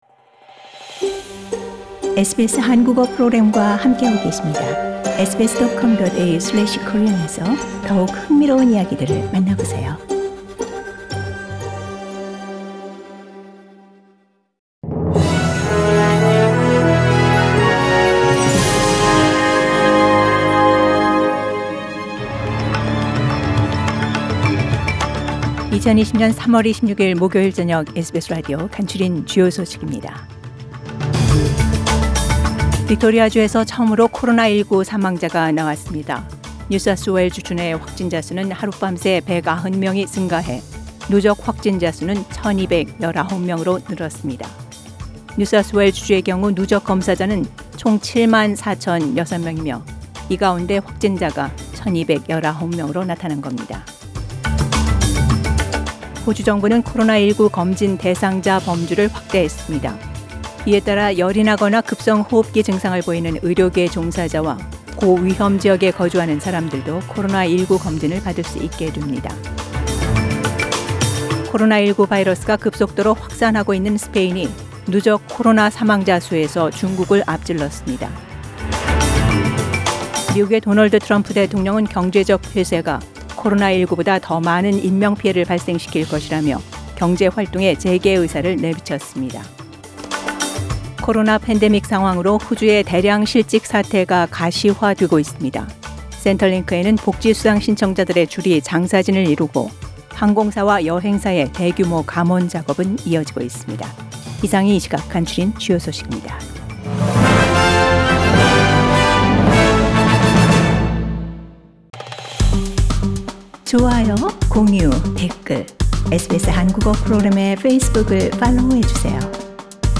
News headlines on 26 March, Thursday from SBS Korean Program